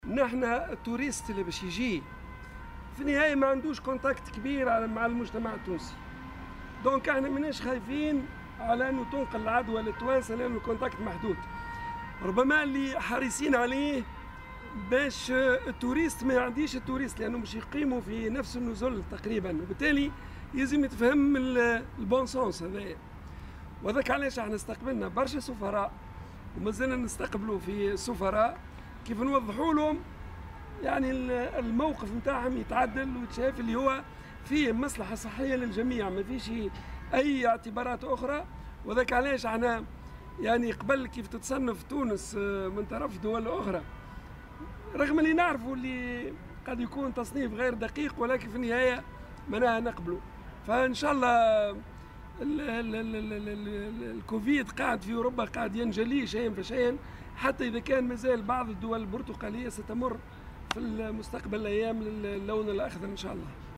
وأوضح خلال ندوة صحفية عقدها اليوم، أن الاتصال بين السياح والمواطنين سيكون محدودا جدّا، لكن يجب الحرص على أن لا تنتقل العدوى بين السياح الذين سيقيمون في النزل نفسها، وفق قوله.